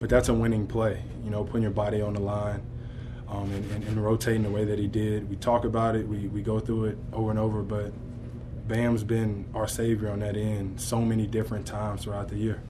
Following the win, Butler praised his team’s performance.
Heat-Post-Adebayo-Makes-Winning-Plays.mp3